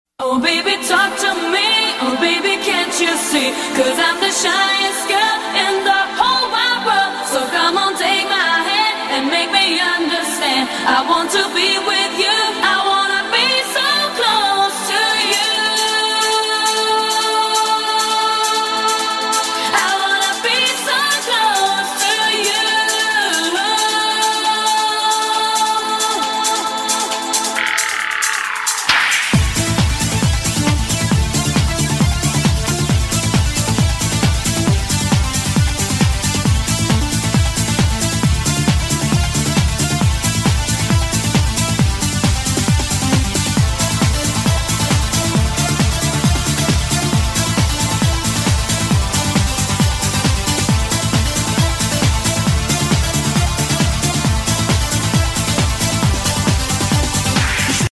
EURO HOUSE